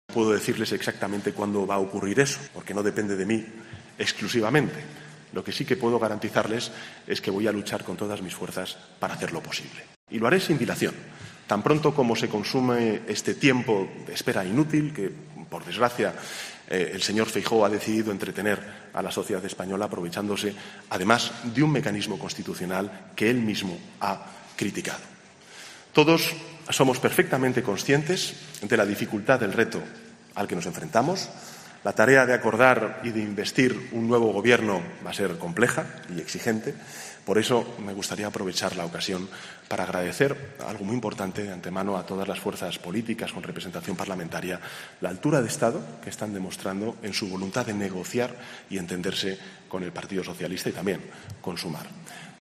Sánchez ha mostrado ese optimismo ante su futura investidura en su intervención en un acto organizado por el Ateneo de Madrid, en el que ha subrayado que su Ejecutivo seguirá trabajando por el progreso y la convivencia con un proyecto que respalda "sin fisuras" a la Constitución.